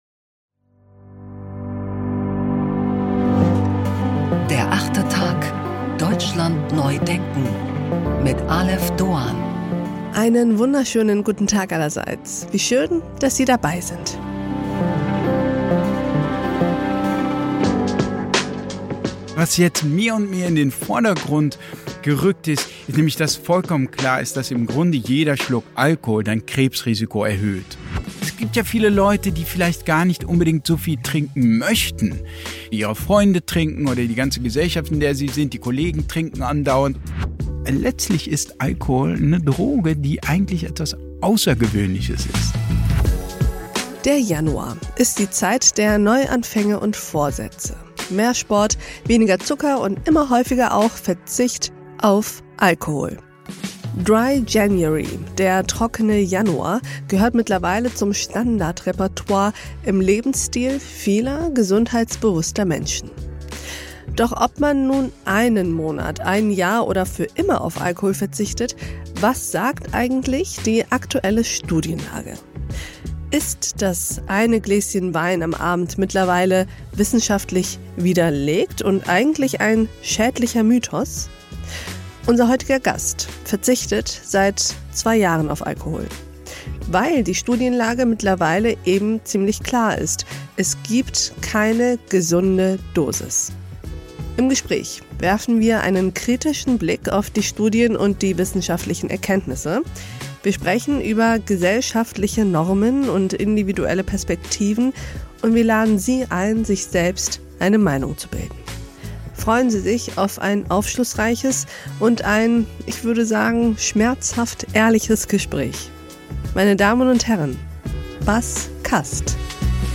Wissenschaftsjournalist Bas Kast über aktuelle Studien und gesellschaftliche Normen.
Ein Gespräch über gesellschaftliche Normen und individuelle Perspektiven.